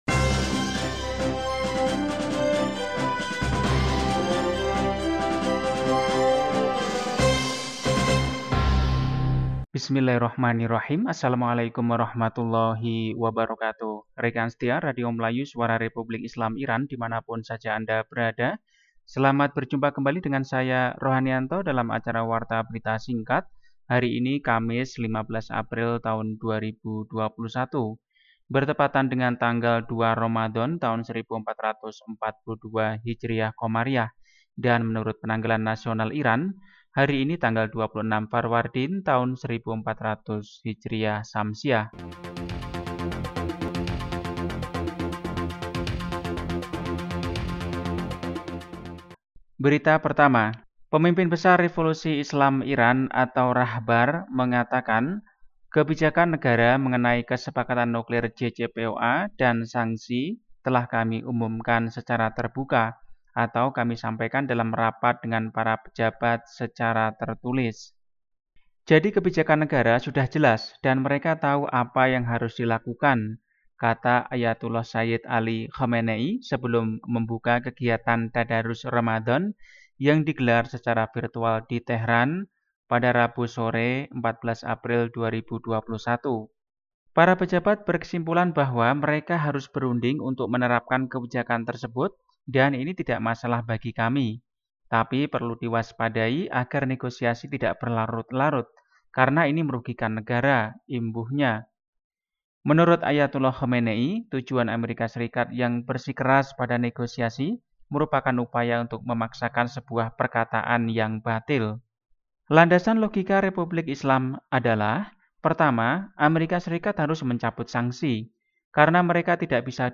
Warta berita hari ini, Kamis, 15 April 2021.